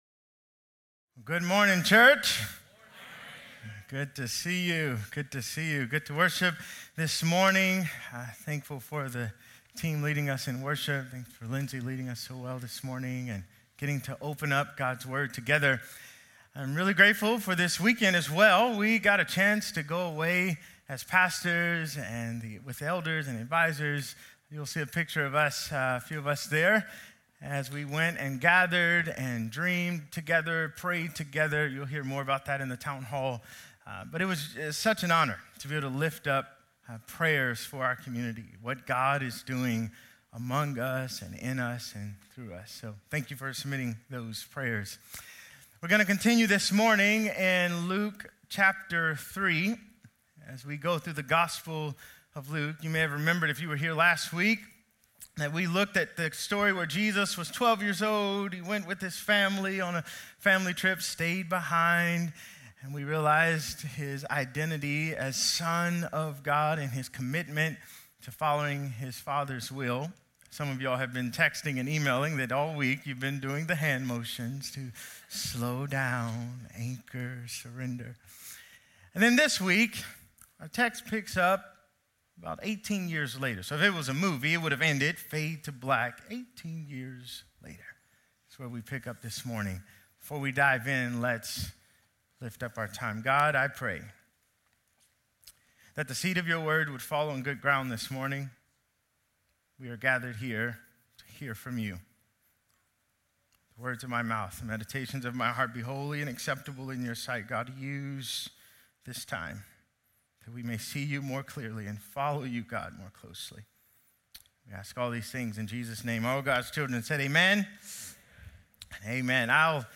Sermons - Peninsula Bible Church